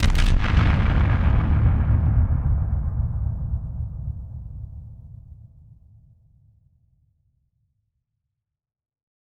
BF_DrumBombC-06.wav